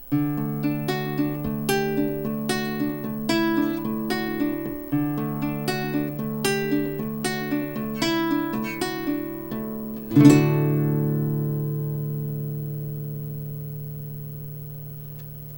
vinyl_record_needle_static_01
33rpm age ambiance dirt dust long-play LP needle sound effect free sound royalty free Memes